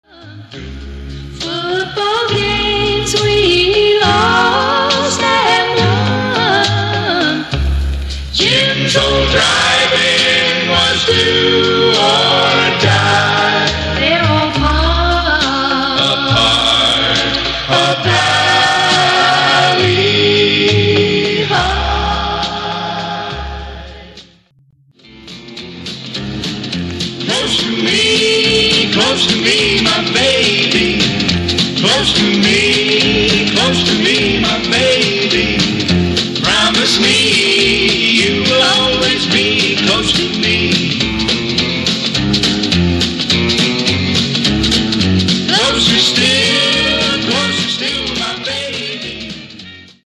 Genre: Teen/Twist/Pop R&R